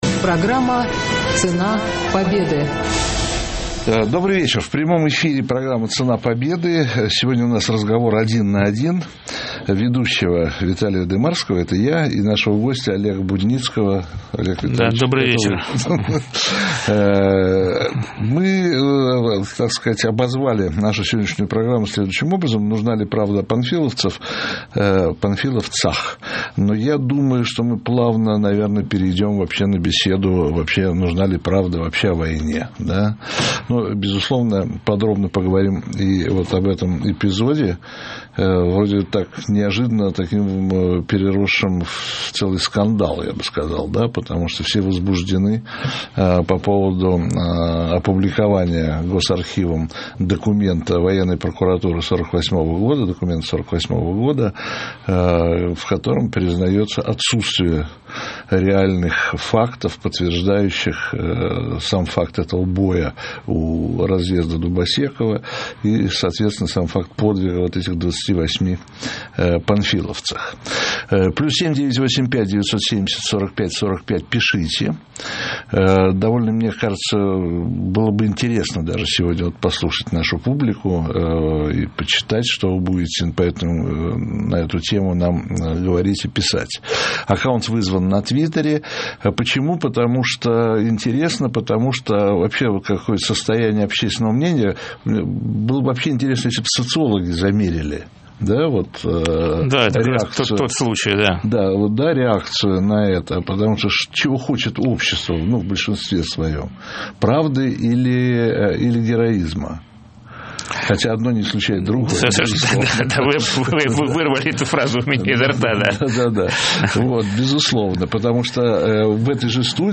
В. Дымарский― Добрый вечер, в прямом эфире программа «Цена Победы». Сегодня у нас разговор один на один ведущего Виталия Дымарского, это я, и нашего гостя Олега Будницкого.